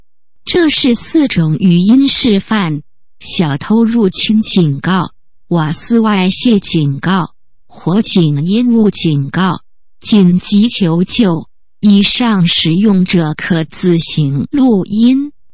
” 有火災”  “瓦斯外洩”  “搶劫” 4種聲音, 狀況發生時系統會依狀況顯示, 並依狀況撥音。
3564區,數位語音防盜-鎮撼科技.mp3